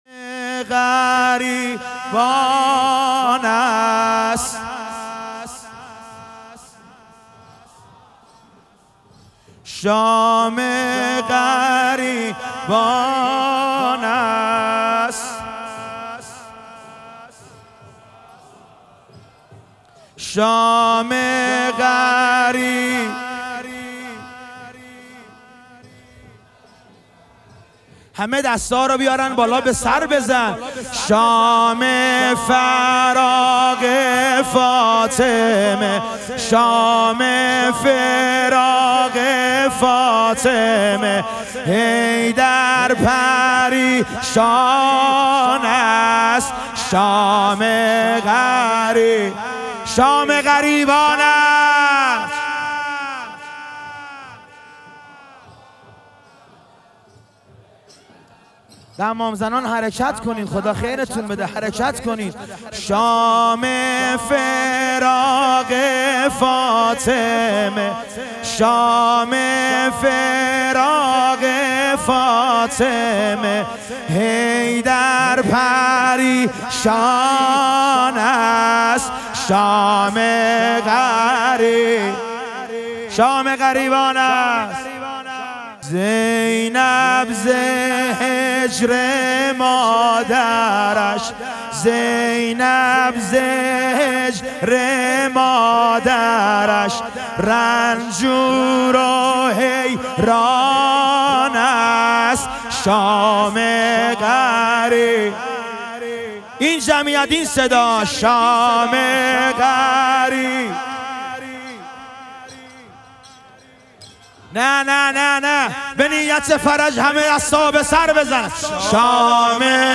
شام غریبان حضرت زهرا علیها سلام - مدح و رجز